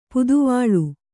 ♪ puduvāḷ